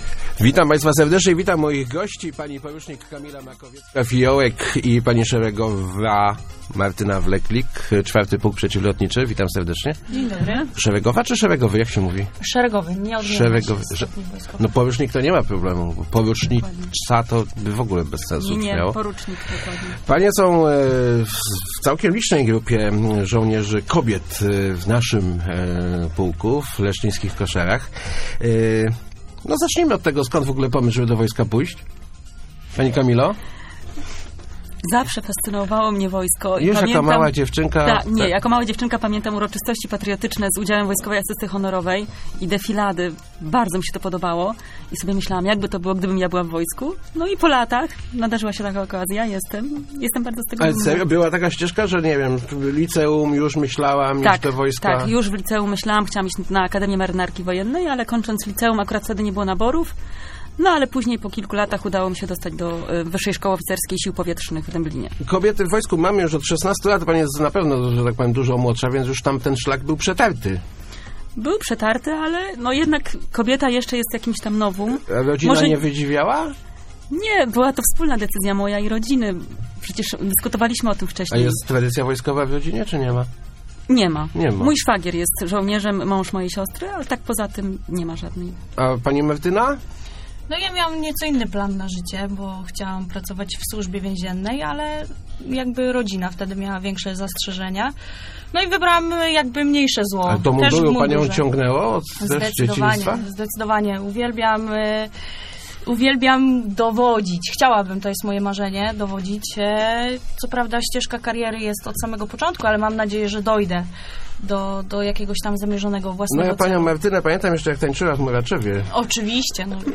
Start arrow Rozmowy Elki arrow Kobiety w mundurach